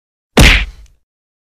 Nhạc Chuông Punch Sound